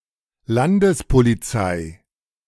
Landespolizei (German for 'state police'; German pronunciation: [ˌlandəspoliˈt͡saɪ]